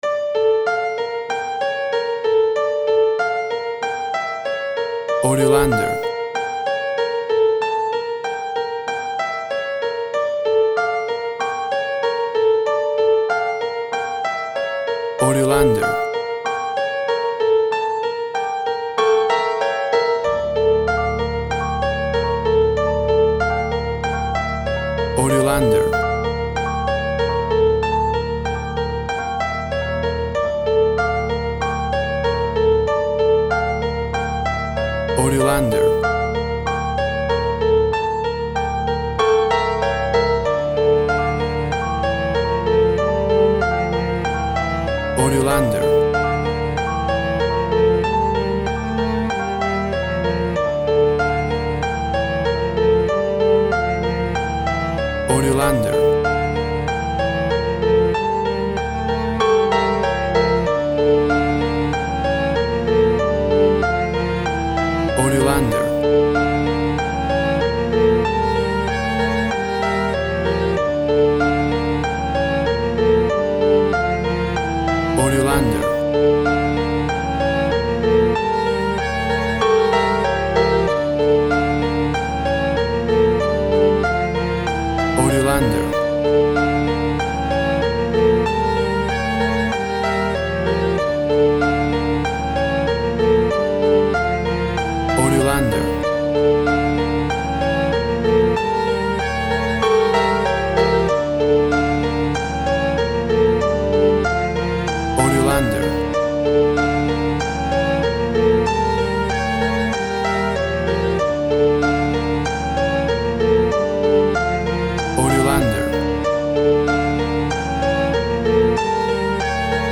WAV Sample Rate 16-Bit Stereo, 44.1 kHz
Tempo (BPM) 96